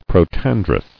[pro·tan·drous]
Pro*tan"drous , a. (Bot.) Proterandrous.